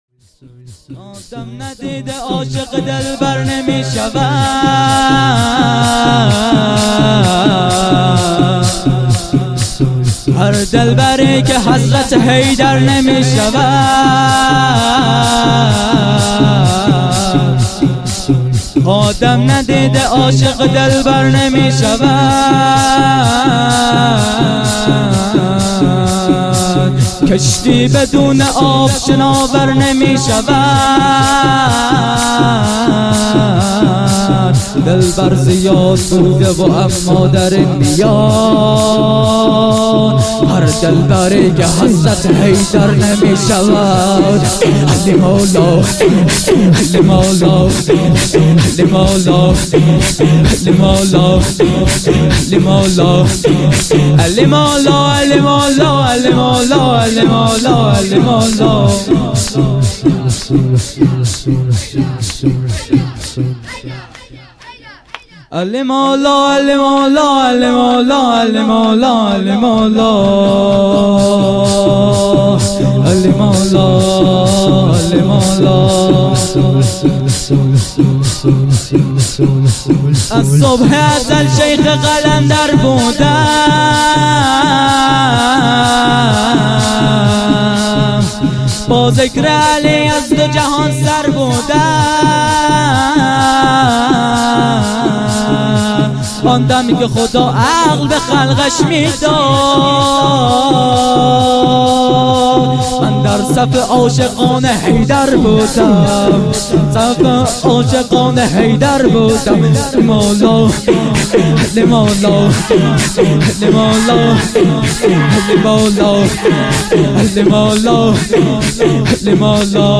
شور۲
شهادت امام حسن عسکری(ع)،97.8.23